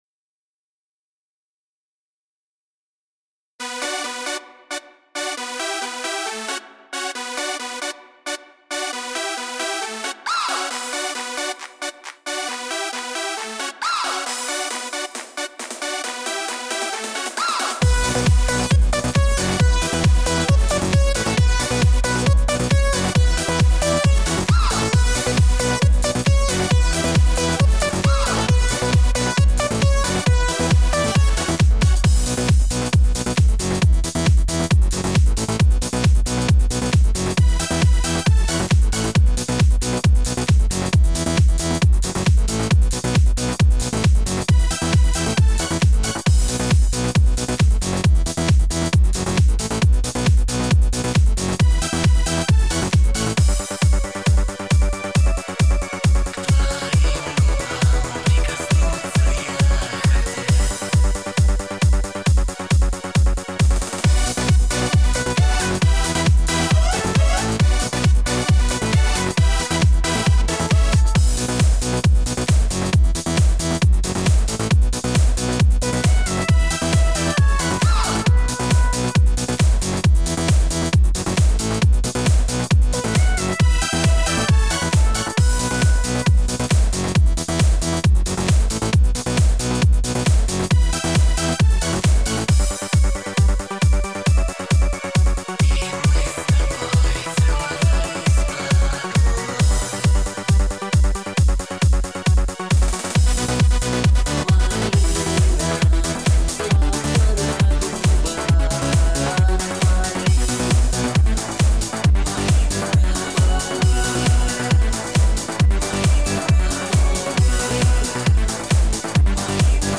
минусовка версия 19796